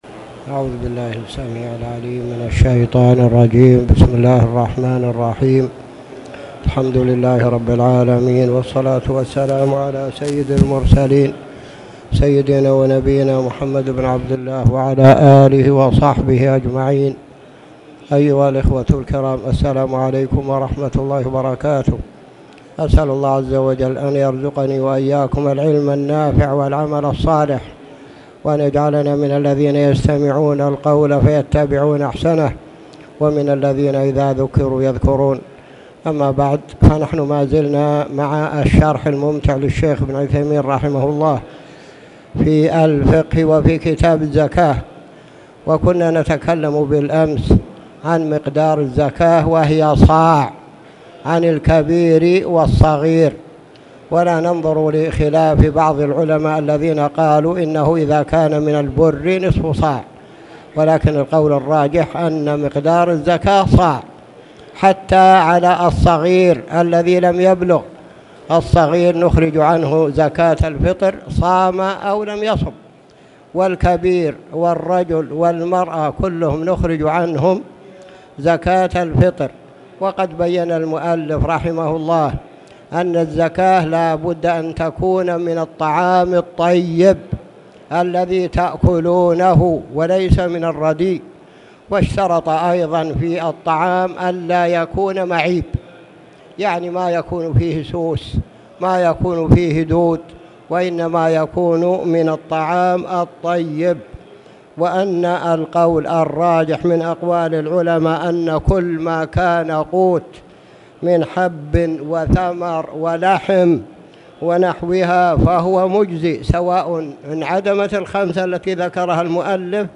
تاريخ النشر ٢٩ جمادى الآخرة ١٤٣٨ هـ المكان: المسجد الحرام الشيخ